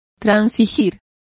Complete with pronunciation of the translation of compromised.